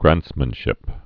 (grăntsmən-shĭp)